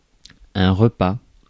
ÄäntäminenFrance (Paris):
• IPA: [ɛ̃ ʁə.pɑ]